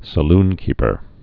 (sə-lnkēpər)